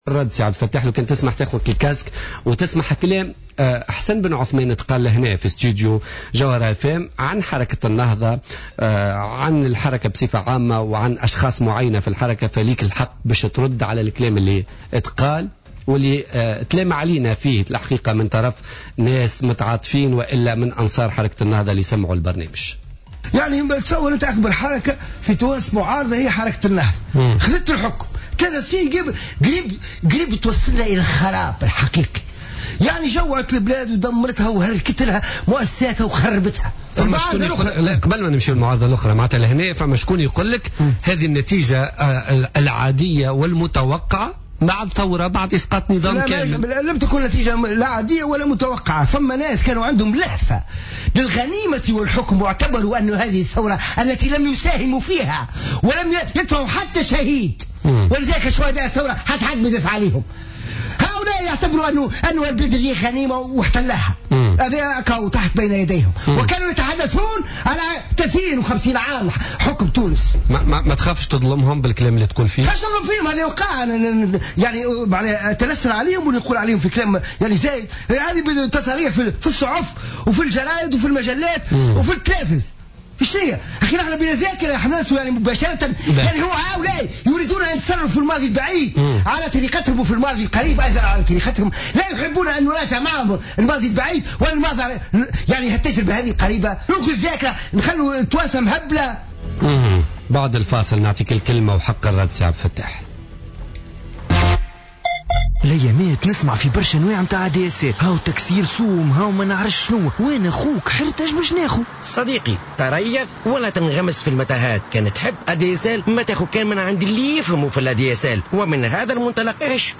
قال نائب رئيس مجلس نواب الشعب عبد الفتاح مورو ضيف بوليتيكا اليوم الخميس 26 مارس 2015...